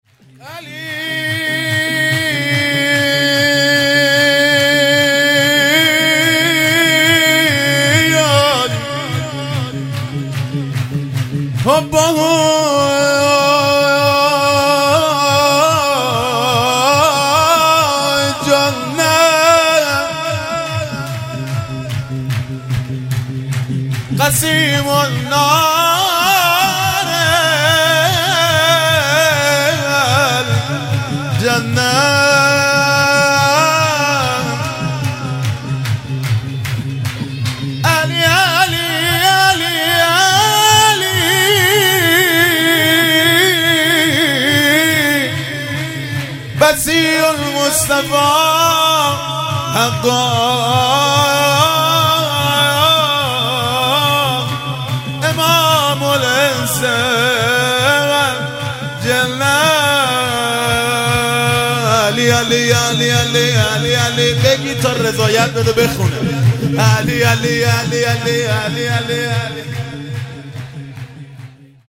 هیئت عاشقان ثارالله کرج (محفل بسیجیان و رهروان شهدا)
جشن میلاد پیامبر(ص) و امام صادق(ع)|جمعه ۲۴ آبانماه ١٣٩۸